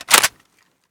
Index of /server/sound/weapons/m98
clipin.mp3